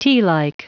Prononciation du mot tealike en anglais (fichier audio)
Prononciation du mot : tealike